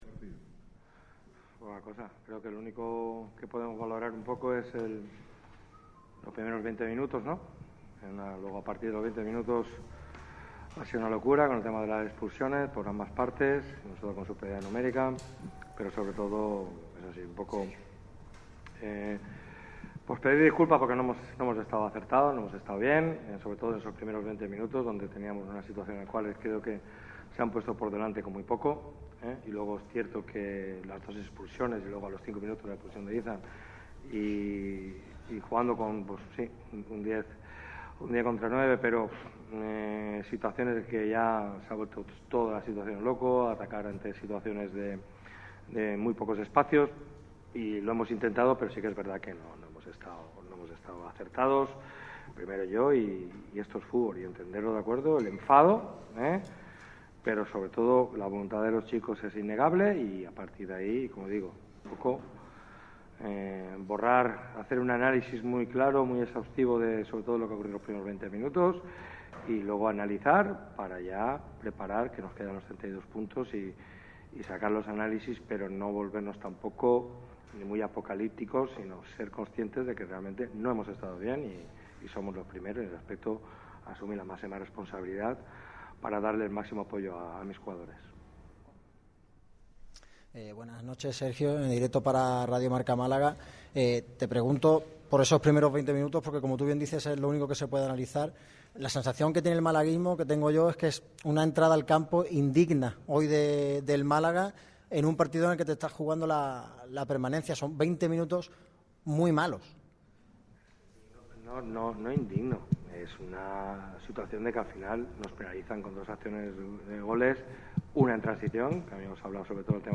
El entrenador del Málaga CF ha comparecido ante los medios tras la derrota a manos del Albacete BP en el partido perteneciente a la jornada 31 (2-0).
Los costasoleños sucumbieron ante un conjunto albaceteño que jugó en inferioridad numérica más de una hora de partido. Un encuentro de absoluta locura que el técnico trató de analizar en la sala de prensa del Estadio Carlos Belmonte.